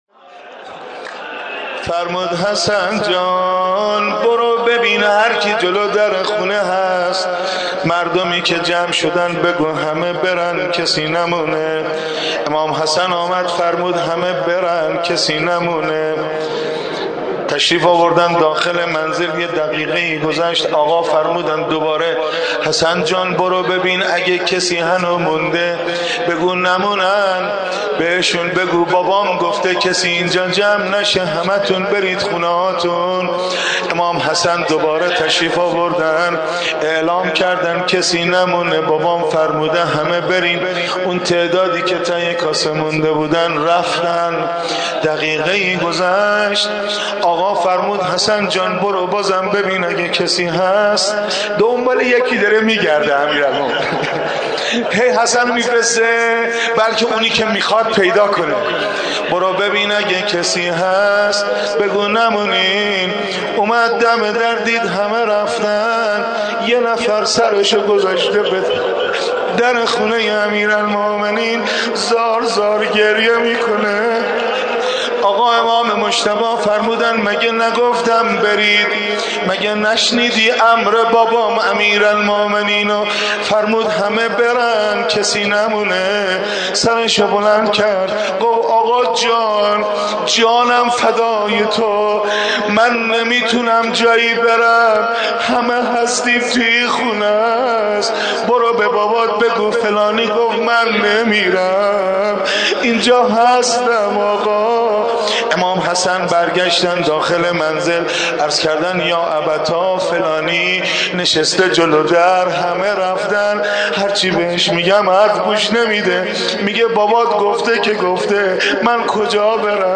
۵. نوحه شب ضربت خوردن امام علی علیه السلام حاج حسن خلج